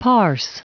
Prononciation du mot parse en anglais (fichier audio)